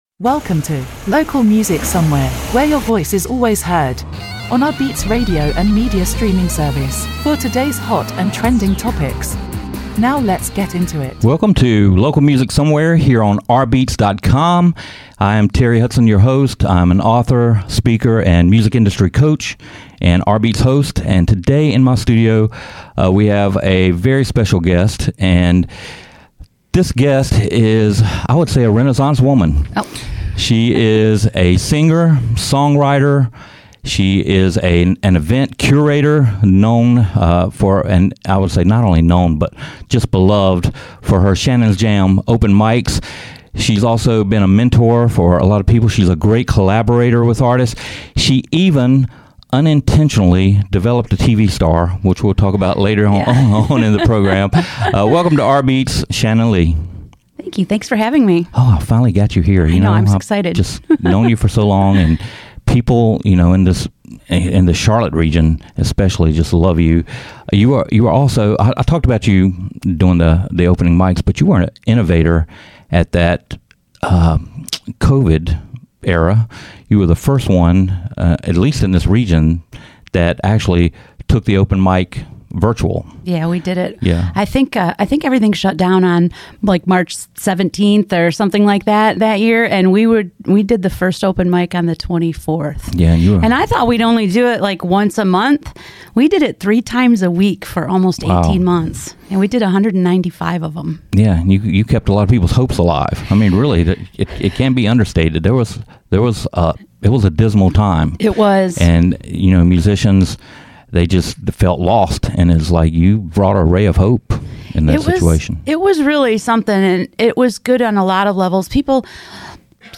LIVE STUDIO INTERVIEW Live Performance